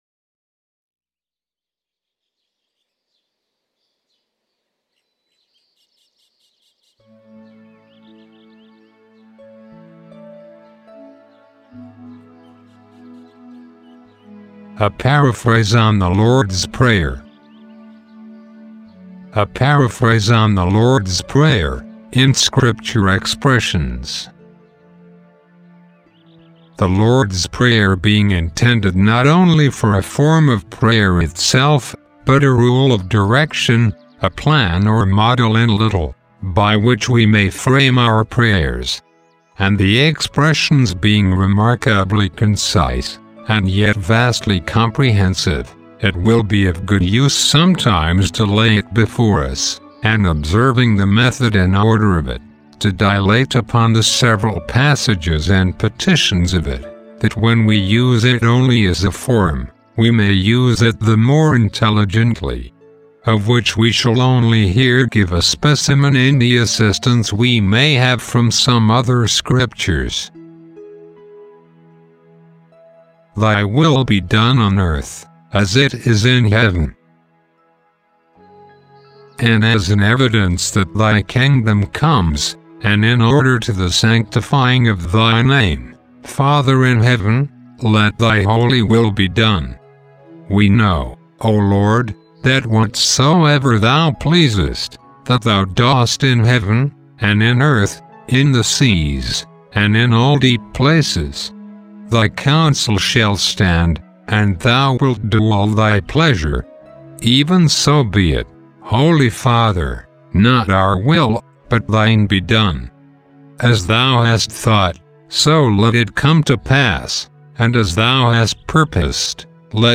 Category Archives: Free audio book